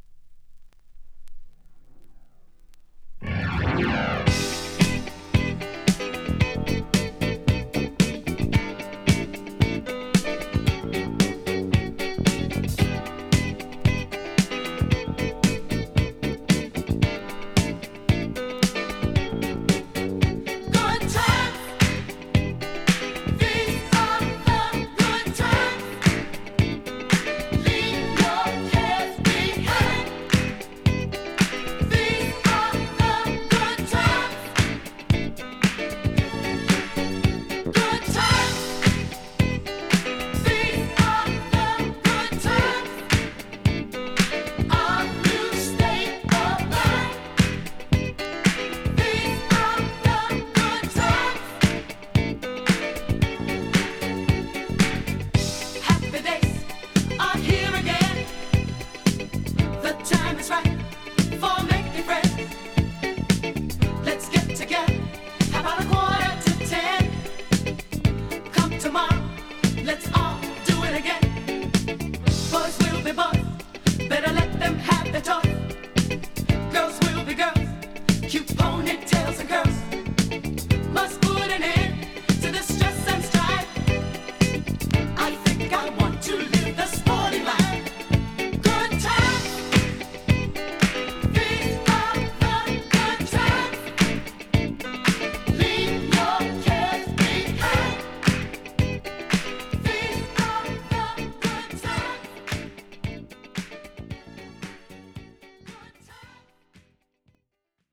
Du har förskjuten ljudbild åt vänster.
Det låter också rätt "försiktigt".